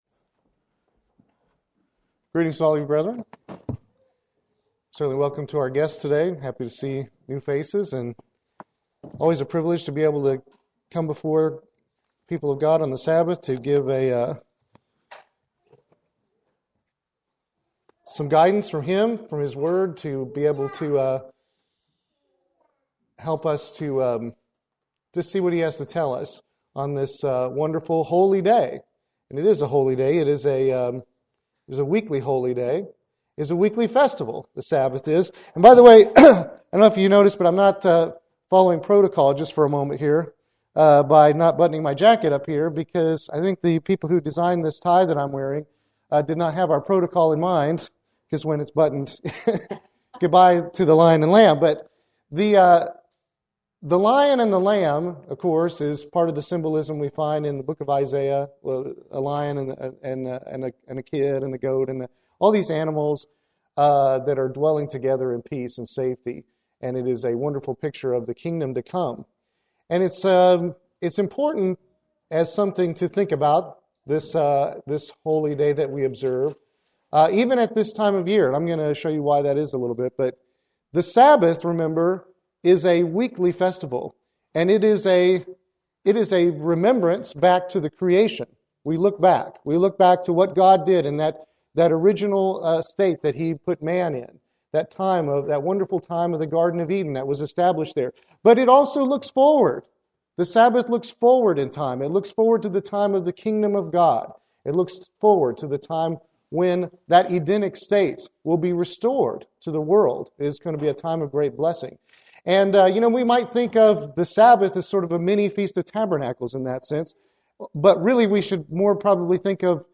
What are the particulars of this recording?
Given in Columbia - Fulton, MO